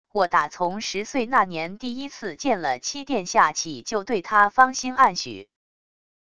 我打从十岁那年第一次见了七殿下起就对他芳心暗许wav音频生成系统WAV Audio Player